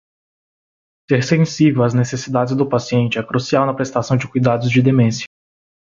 Pronúnciase como (IPA)
/sẽˈsi.vew/